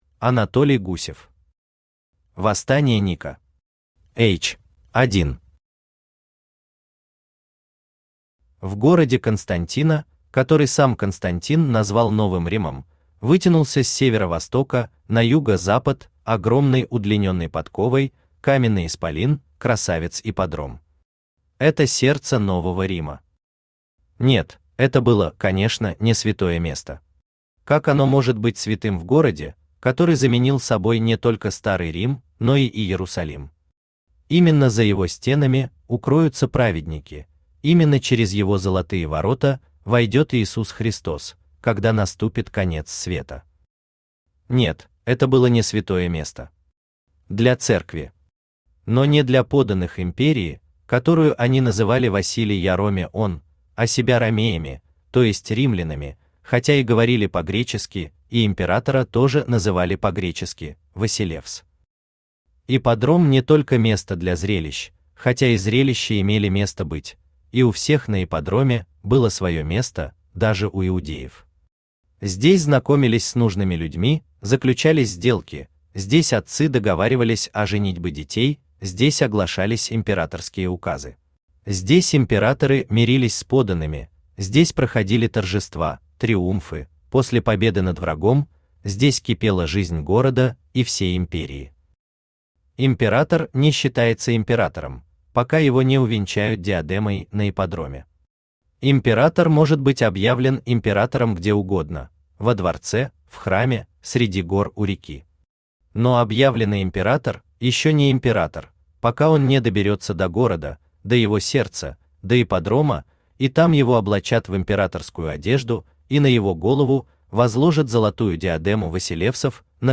Аудиокнига Восстание Ника. H εξέγερση Νικα | Библиотека аудиокниг
H εξέγερση Νικα Автор Анатолий Алексеевич Гусев Читает аудиокнигу Авточтец ЛитРес.